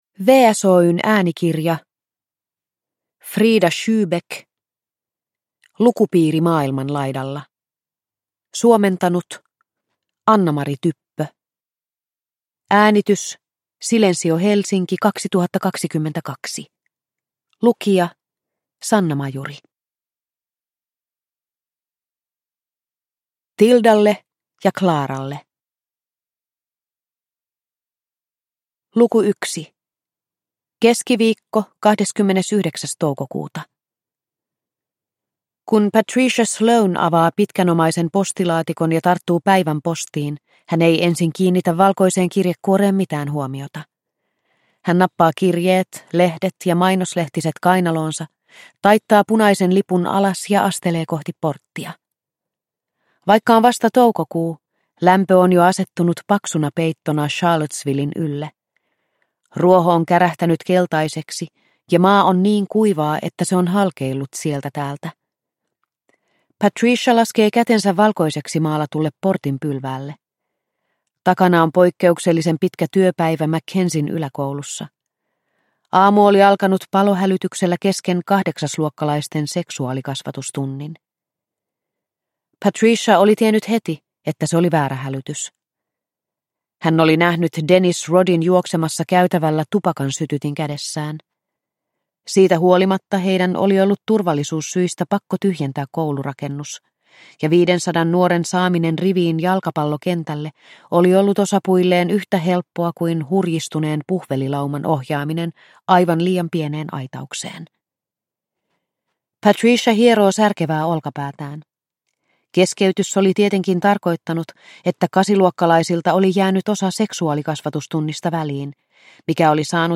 Lukupiiri maailman laidalla – Ljudbok – Laddas ner